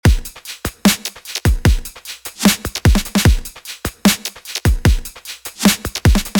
Drums (FxChain - Drum Box)
Drums-FxChain-Drum-Box.mp3